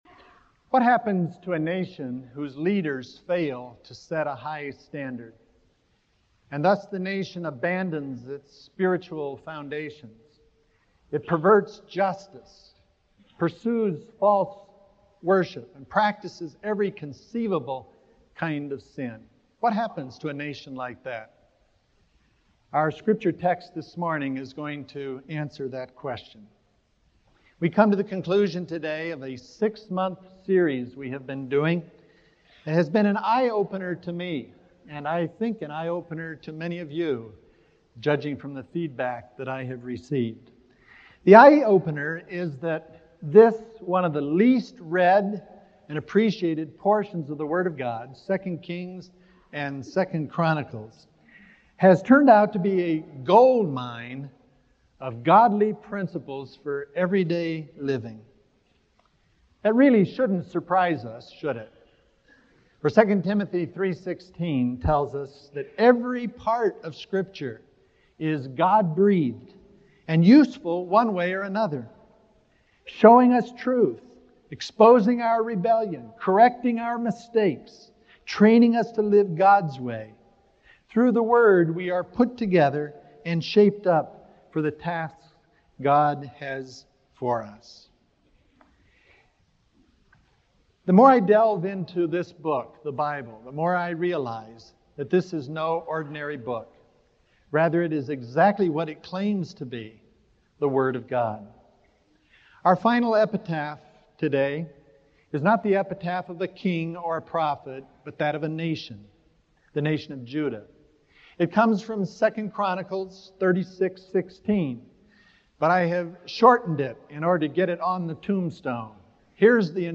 This morning we come to the conclusion of a six-month series of sermons that has been an eye-opener to me and to many of you, as judged by the feedback you have given me.